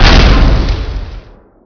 explode_death.wav